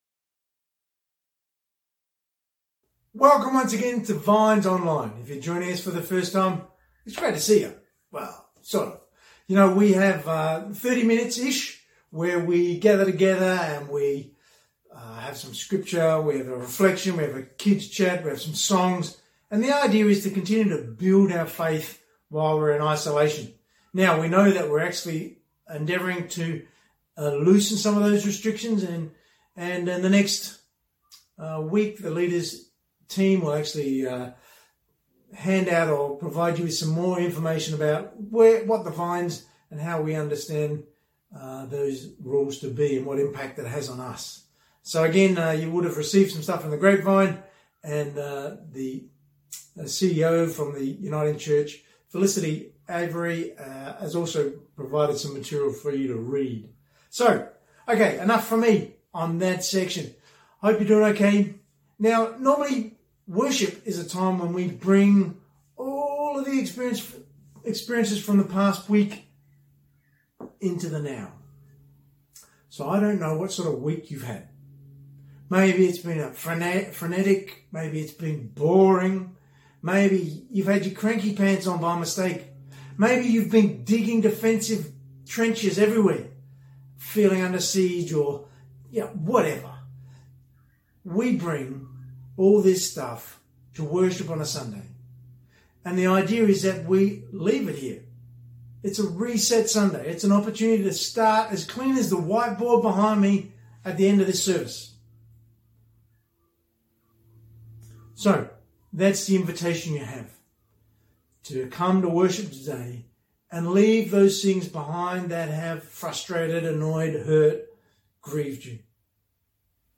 Sermon: Passing on God’s Love (Download)